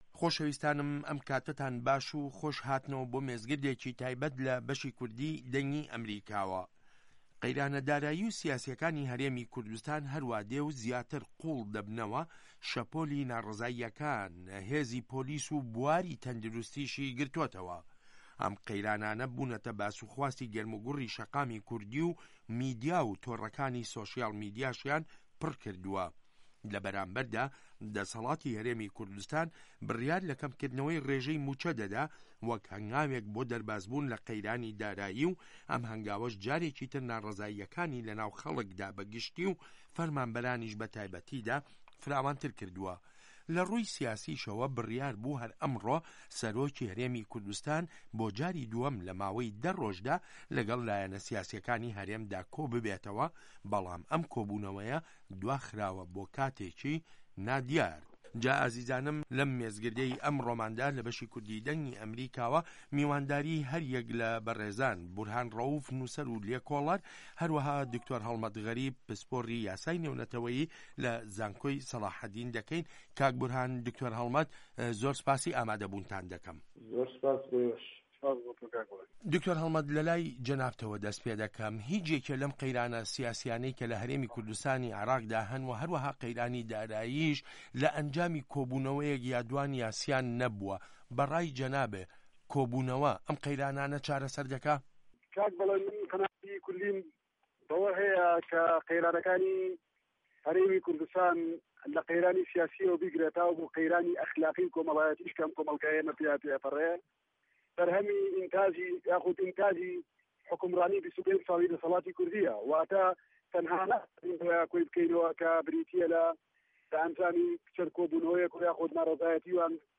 مێزگرد: